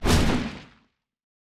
Special & Powerup (18).wav